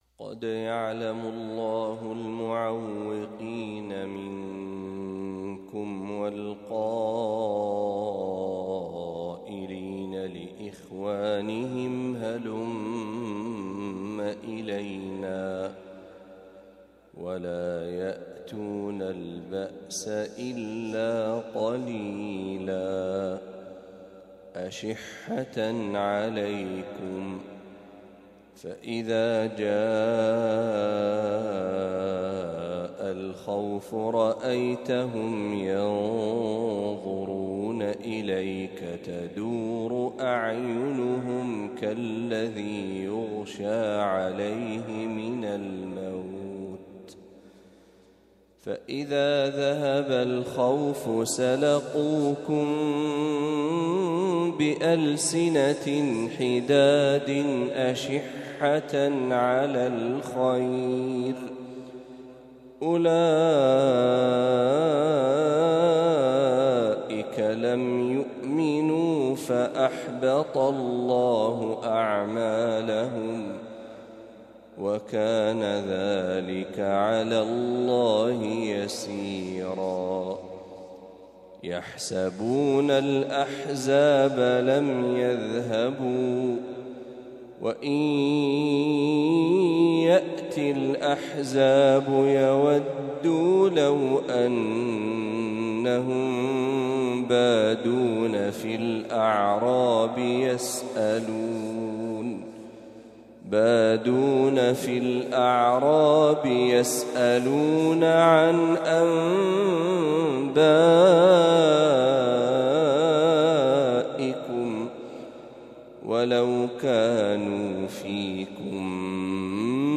ما تيسر من سورة الأحزاب | فجر الأحد ٢٨ صفر ١٤٤٦هـ > 1446هـ > تلاوات الشيخ محمد برهجي > المزيد - تلاوات الحرمين